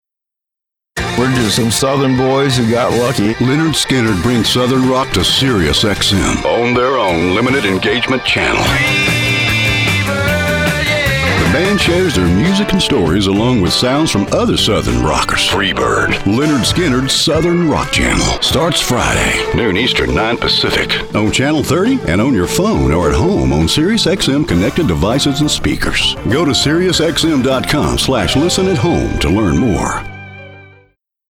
Slight southern, Texas accent
Middle Aged